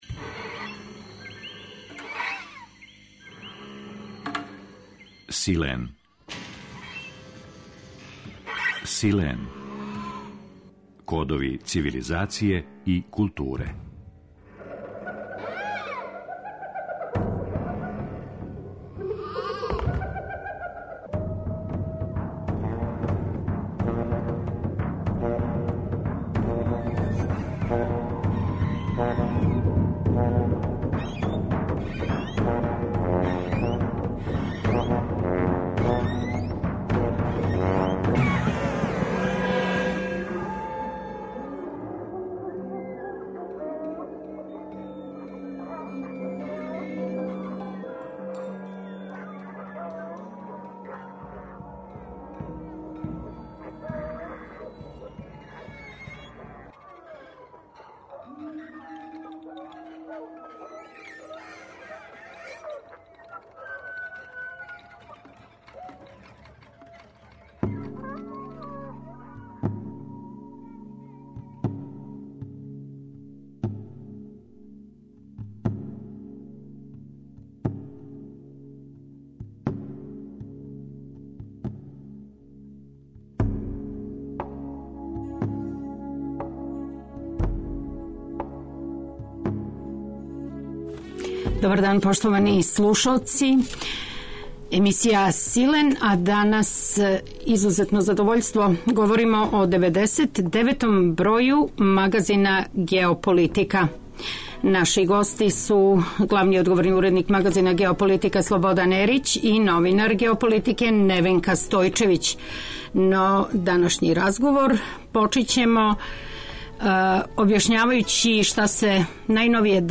Наслов емисије – Силен (агалма, phantasma), упућује на моћ радијског израза, који се заснива на говору, да понуди significatio (унутрашње значење) својим слушаоцима и преда им га на чување, насупрот импотентној зависности у коју их уводи екранска слика (поларна инерција).